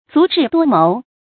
注音：ㄗㄨˊ ㄓㄧˋ ㄉㄨㄛ ㄇㄡˊ
足智多謀的讀法